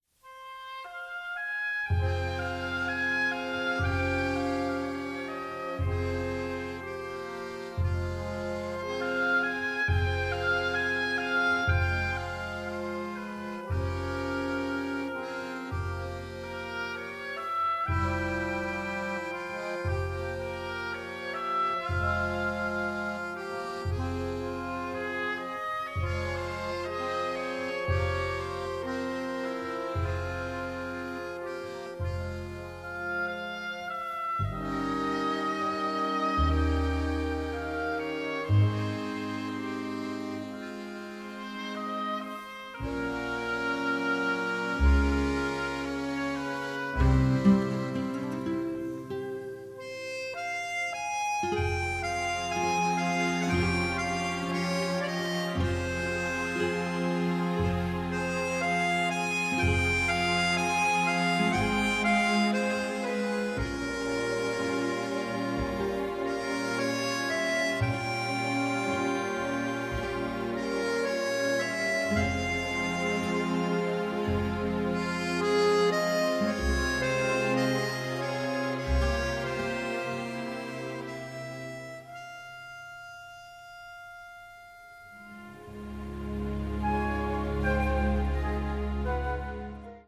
original motion picture score
the score is operatic in its power